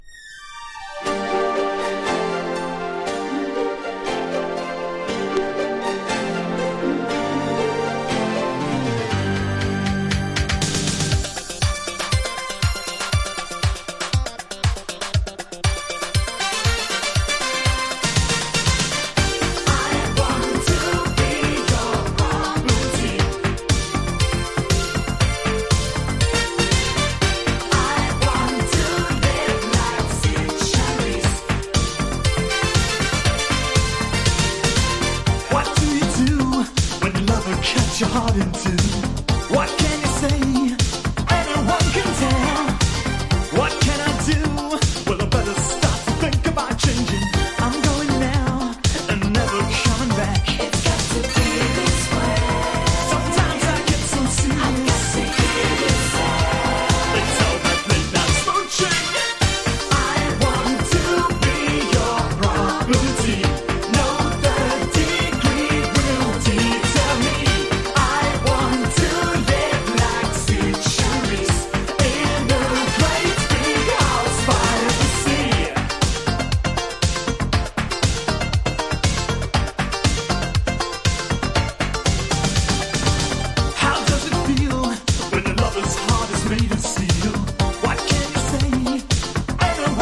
80年代っぽいアップ・テンポなダンス・ナンバー!!